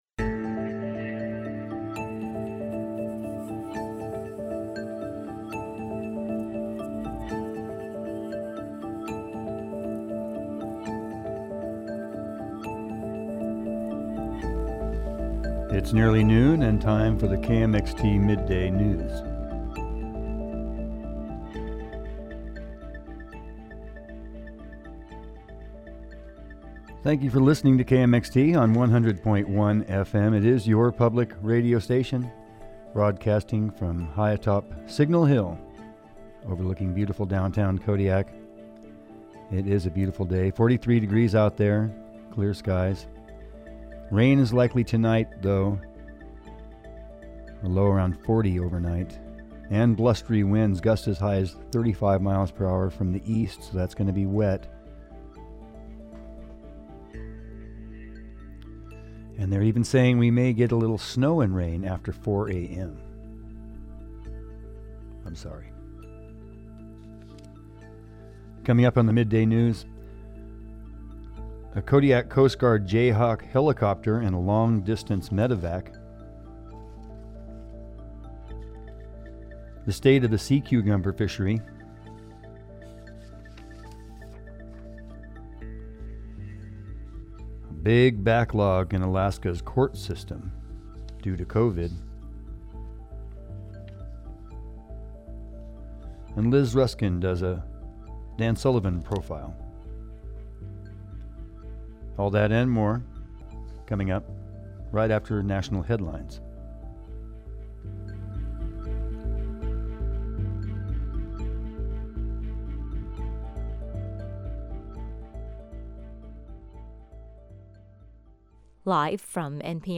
Stay tuned for this Tuesday’s midday news report